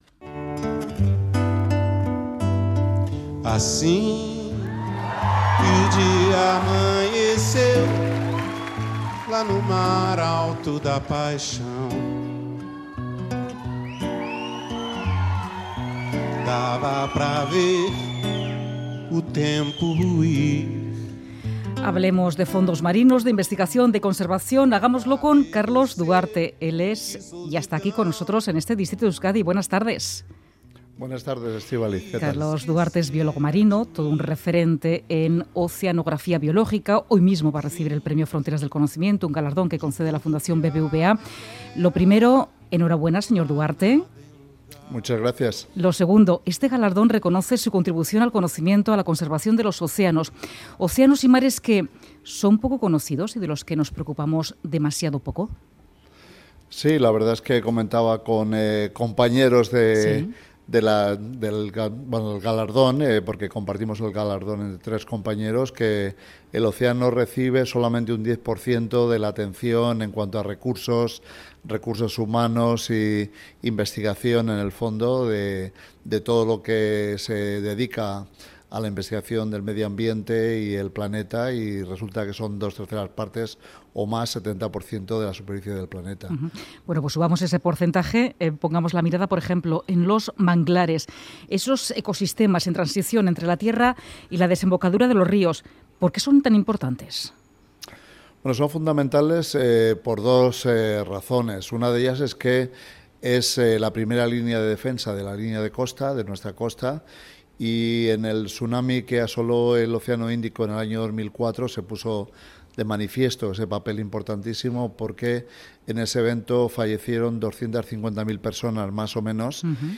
Radio Euskadi ENTREVISTAS